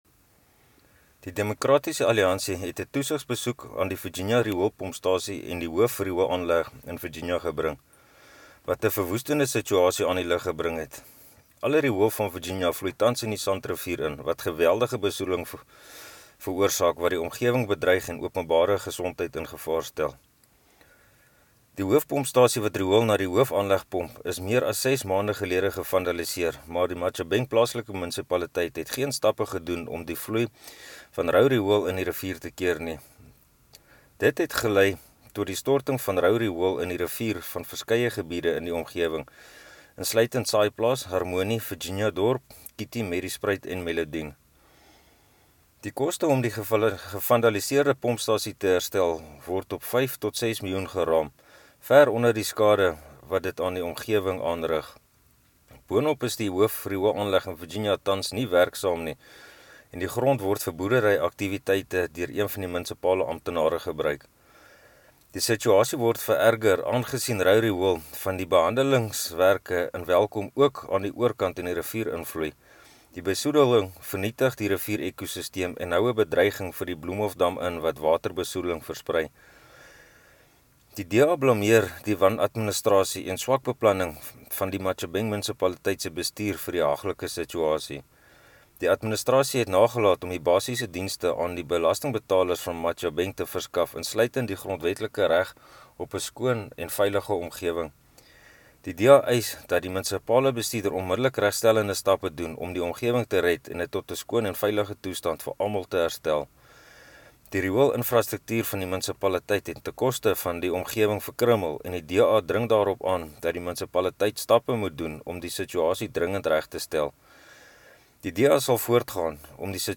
Afrikaans soundbites by Cllr Hansie du Plessis and